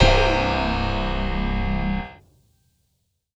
SOUTHSIDE_percussion_is_it_a_piano_C.wav